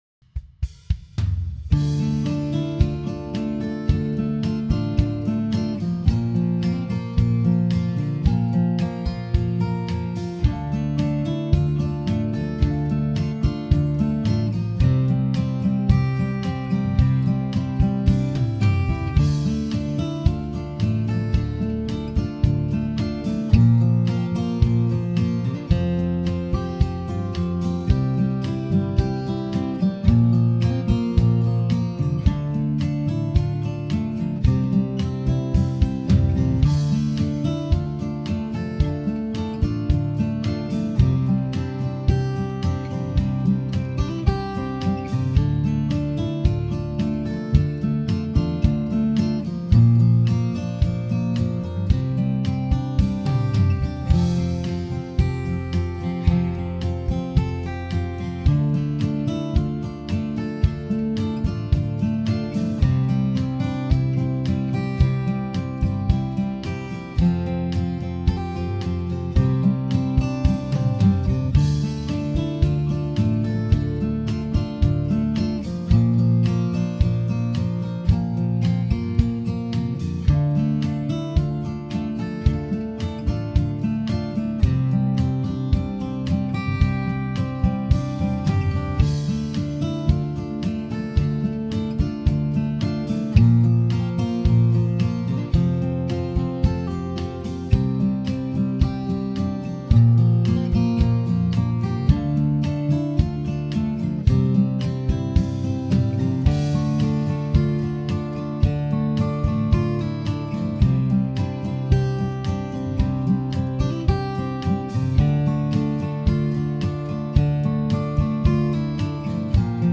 Home > Music > Rock > Bright > Smooth > Medium